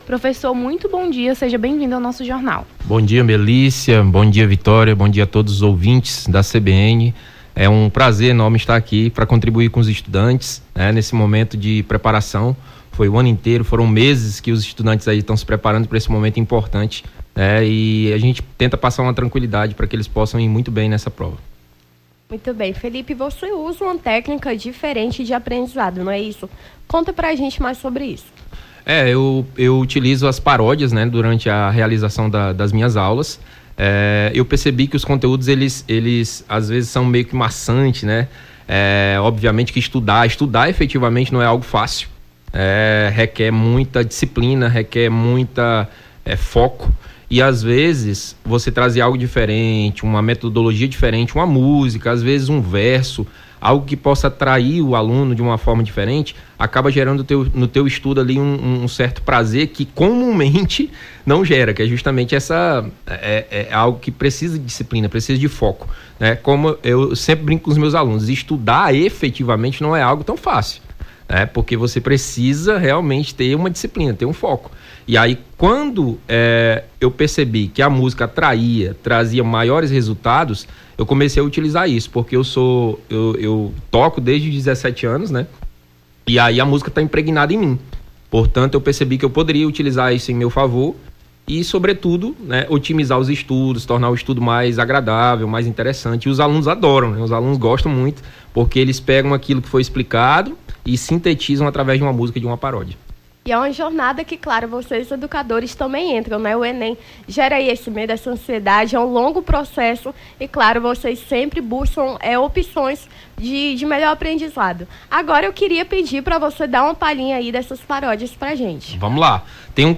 Nome do Artista - CENSURA - ENTREVISTA (DICAS ENEM) 03-11-23.mp3